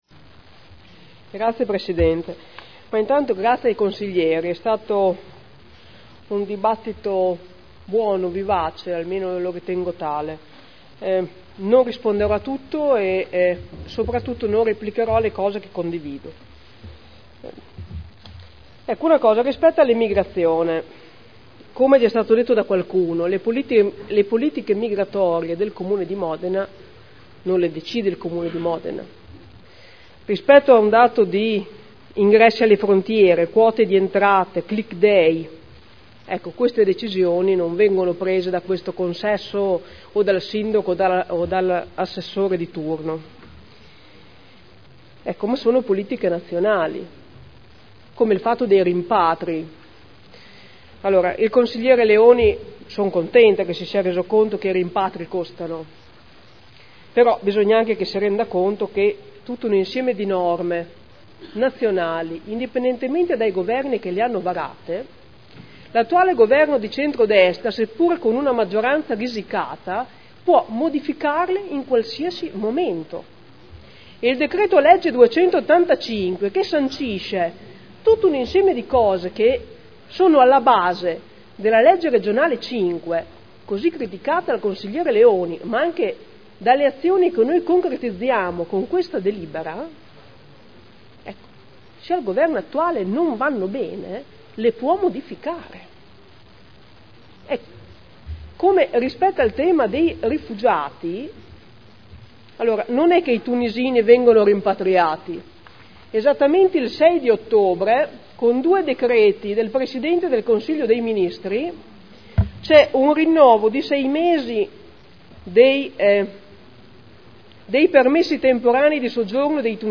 Francesca Maletti — Sito Audio Consiglio Comunale